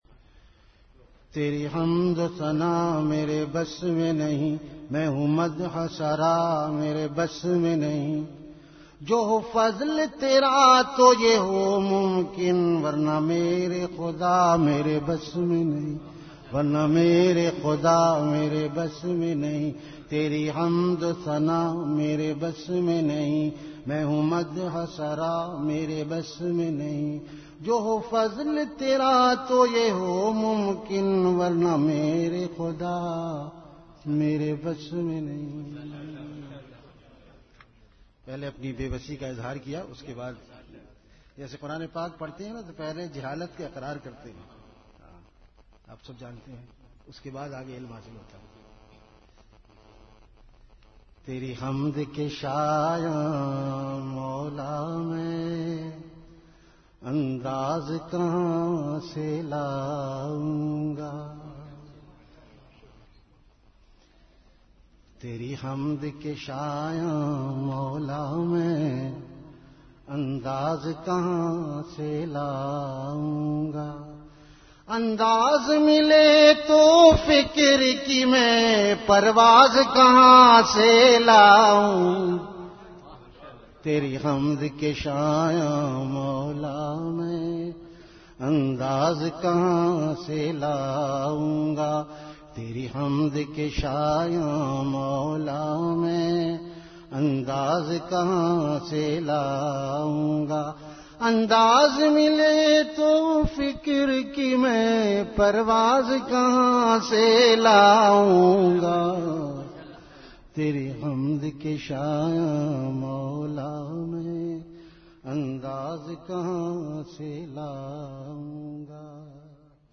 Category Ashaar
Venue Home Event / Time After Isha Prayer